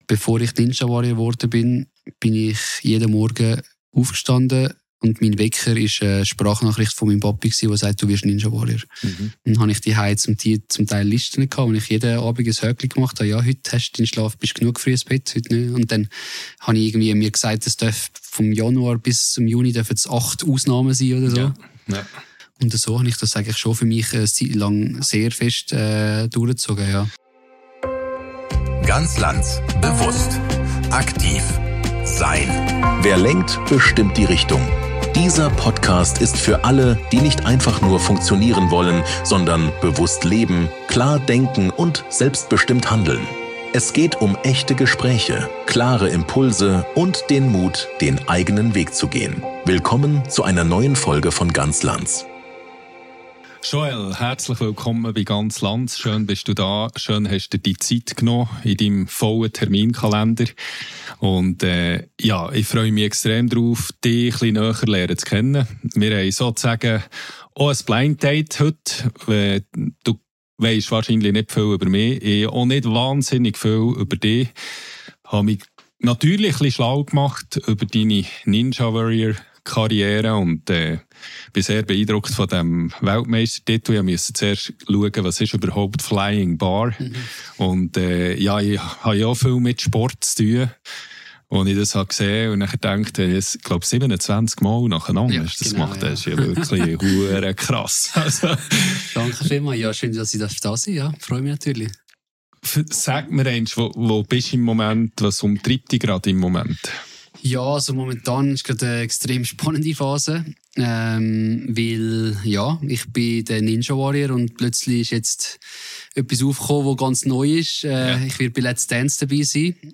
Es geht um Durchhaltevermögen, Selbstführung und die Frage: Wie bleibst du dir treu - wenn Leistung zur Erwartung wird? Ein ehrliches Gespräch über Willenskraft, Selbstführung und Balance.